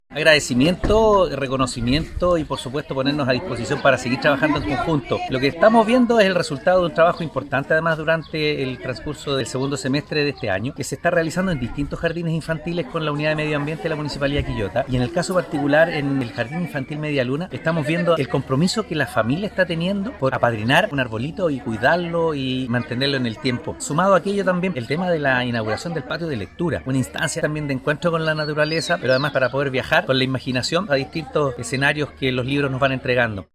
La ceremonia fue encabezada por el alcalde de Quillota, Óscar Calderón Sánchez, quien valoró el impulso a la educación ambiental que se está dando en el establecimiento anfitrión, así como en otros jardines de la comuna, con diversos proyectos que promueven el cuidado del entorno y la generación de una conciencia ecológica entre los niños de la comuna, desde muy temprana edad.
03-ALCALDE-Oscar-Calderon.mp3